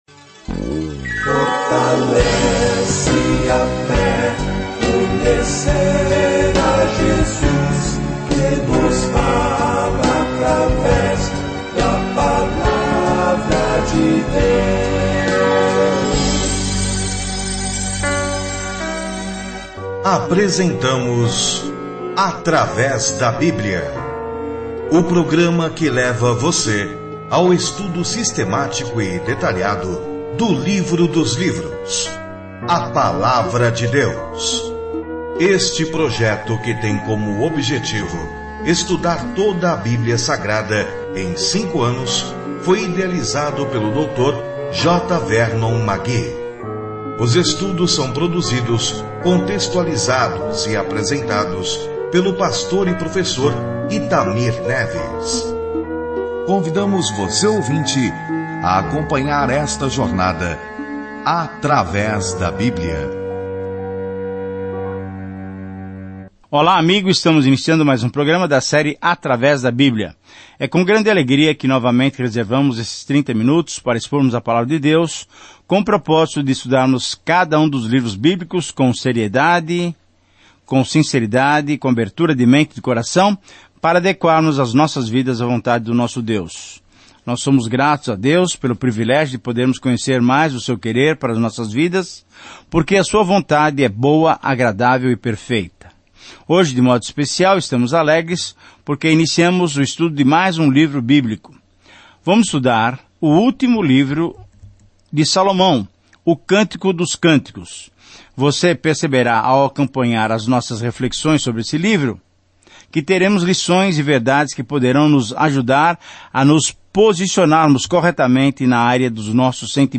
As Escrituras Cântico dos Cânticos 1 Começar esse Plano Dia 2 Sobre este Plano Cântico de Salomão é uma pequena canção de amor que celebra o amor, o desejo e o casamento com uma ampla comparação com como Deus nos amou primeiro. Viaje diariamente por Cantares de Salomão enquanto ouve o estudo em áudio e lê versículos selecionados da palavra de Deus.